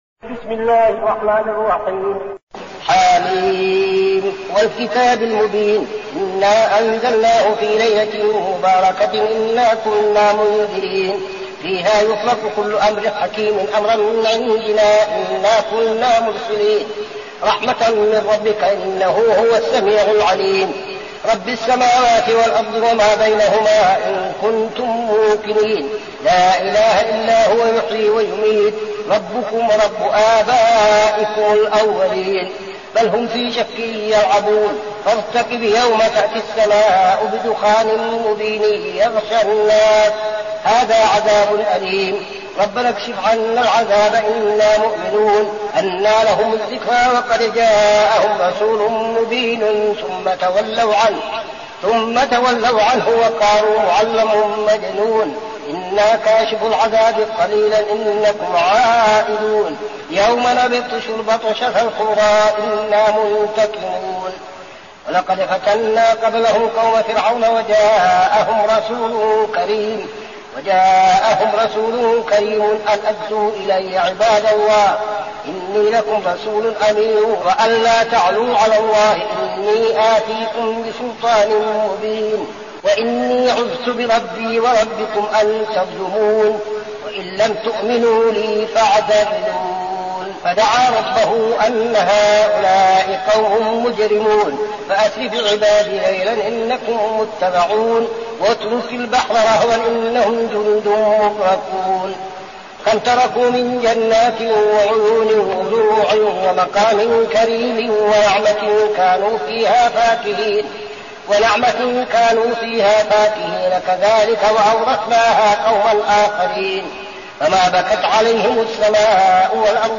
المكان: المسجد النبوي الشيخ: فضيلة الشيخ عبدالعزيز بن صالح فضيلة الشيخ عبدالعزيز بن صالح الدخان